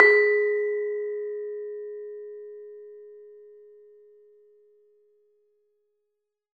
LAMEL G#3 -L.wav